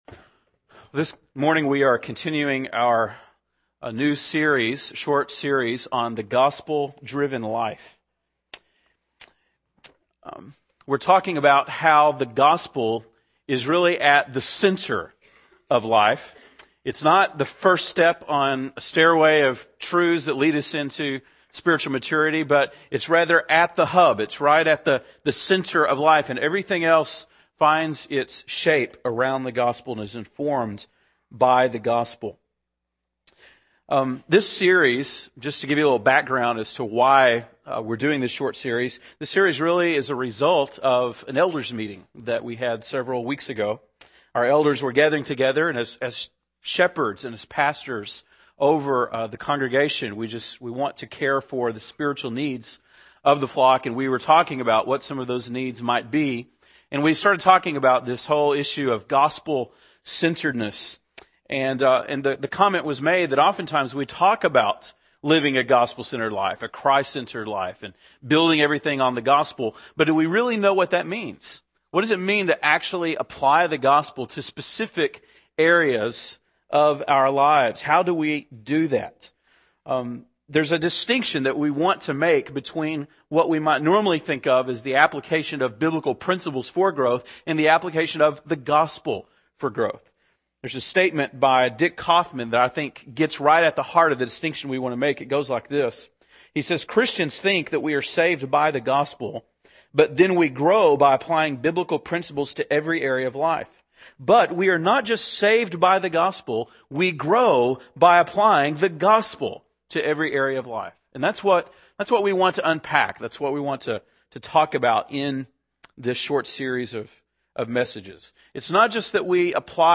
April 26, 2009 (Sunday Morning)